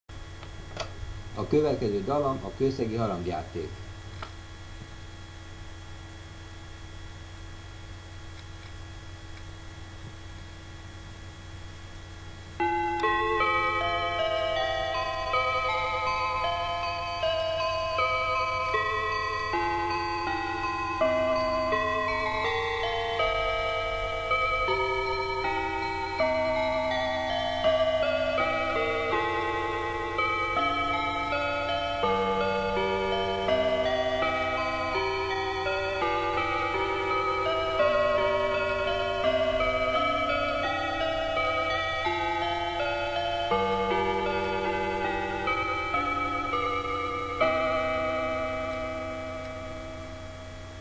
· klasszikus, hangolt bronz harangokkal,
· elektronikus hangrendszer,
· Kőszegi római katolikus templom eredeti harangjátéka (
26_koszegi_harangjatek.wma